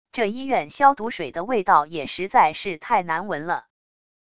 这医院消毒水的味道也实在是太难闻了wav音频生成系统WAV Audio Player